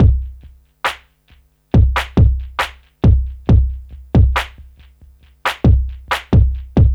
C E.BEAT 4-L.wav